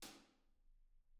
R_B Hi-Hat 05 - Room.wav